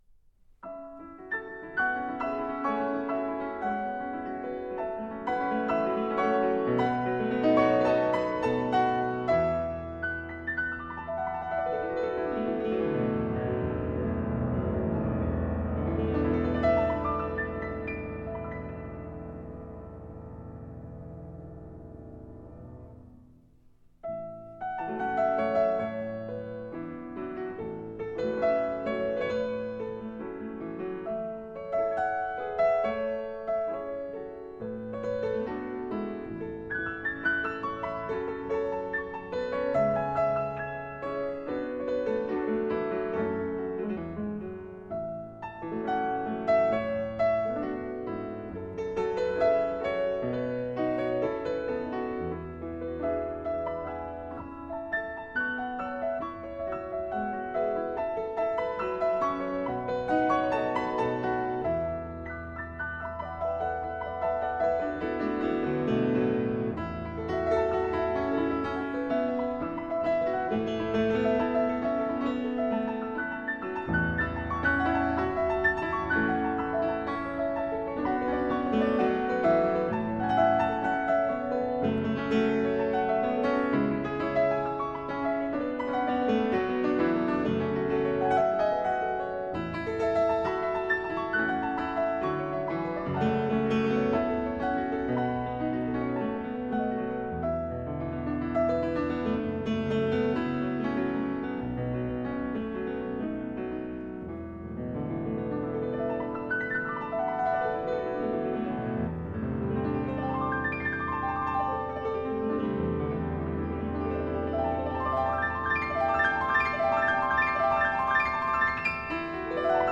钢琴与中国经典乐曲的完美结合！
中国风珍藏钢琴巨作！